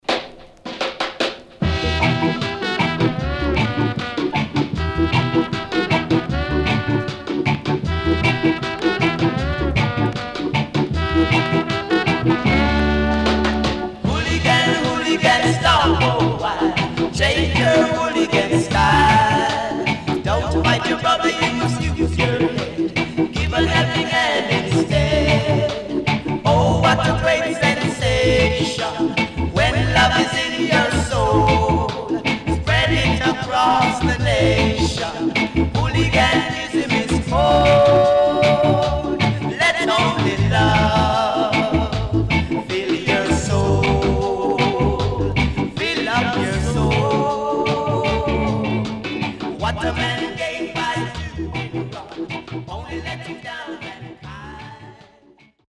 CONDITION SIDE A:VG(OK)〜VG+
W-Side Great Killer Vocal
SIDE A:少しプチノイズ入ります。